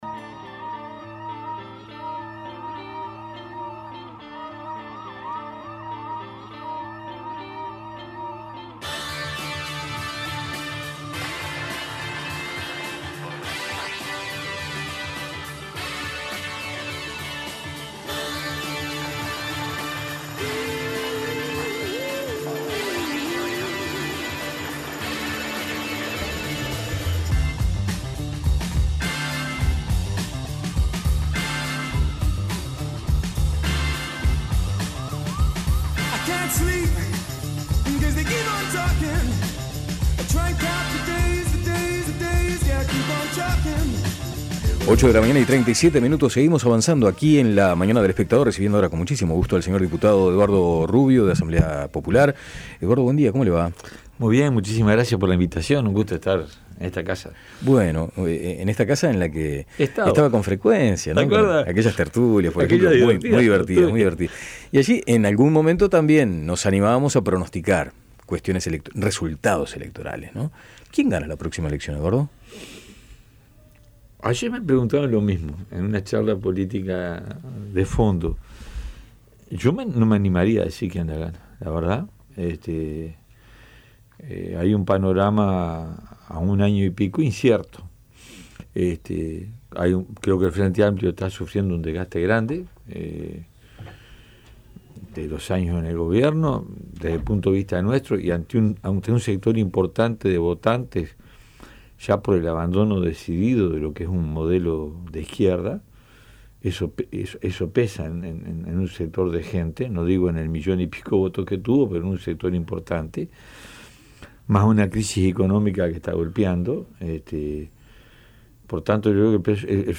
Rubio dijo en La Mañana de El Espectador que el gobierno debería irse con la promesa cumplida del 6% genuino del PBI para la Educación, considerando solamente a la ANEP y la UDELAR, en lugar de defender un 5,2%, al cual calificó como una gran mentira.